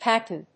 /ˈpætʌn(米国英語)/